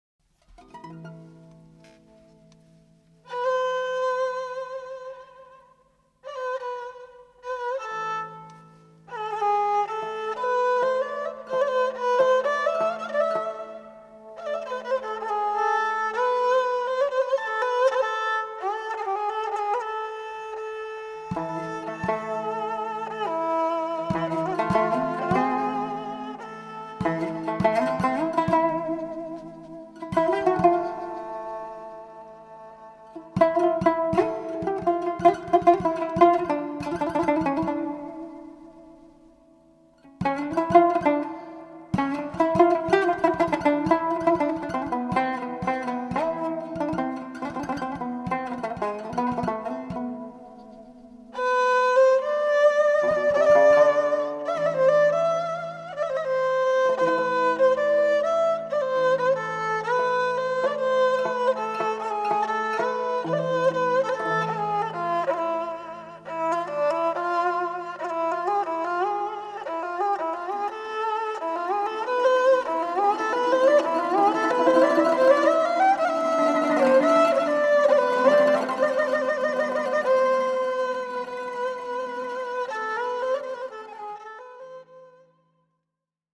Genre: Turkish & Ottoman Classical.
Tanbur
Classical Kemençe